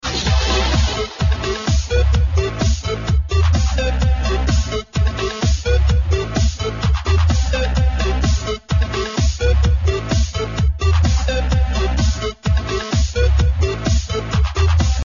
Electro!